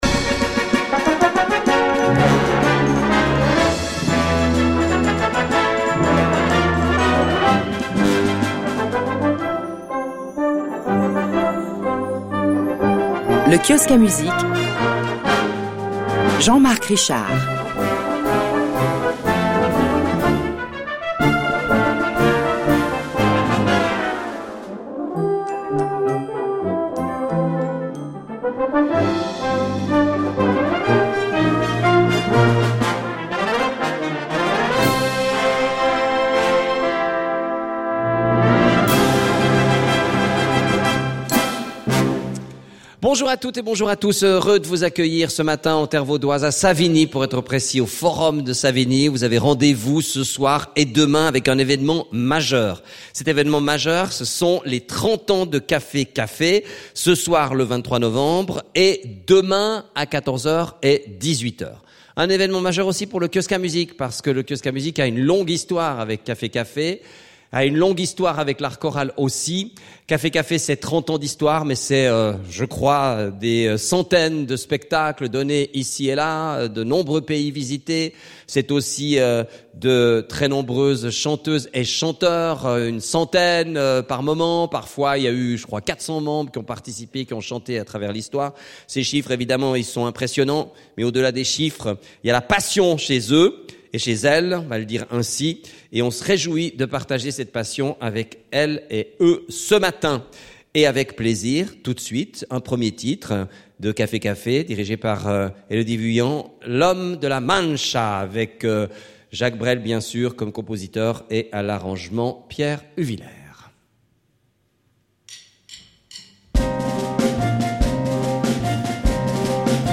Concerts du 30ème anniversaire, Savigny/VD | Café-Café | groupe vocal